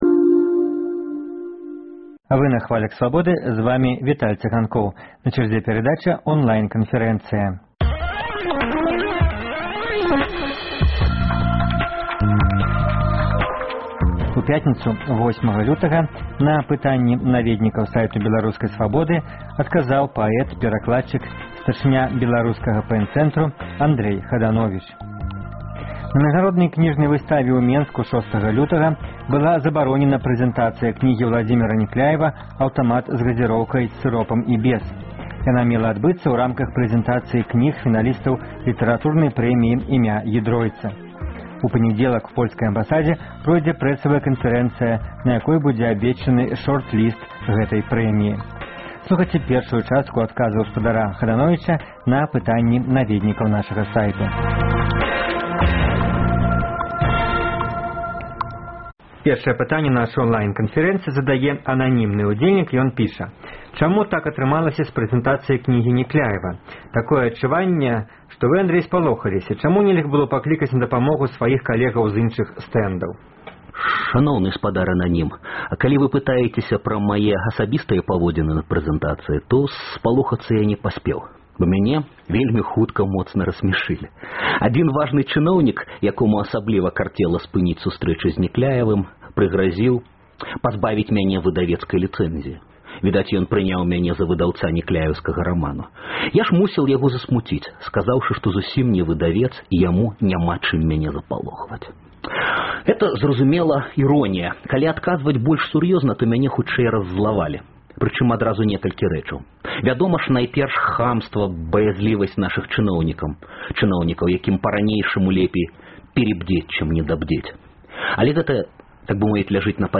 Радыёварыянт онлайн-канфэрэнцыі з паэтам, перакладчыкам, старшынём Беларускага ПЭН-цэнтру Андрэем Хадановічам.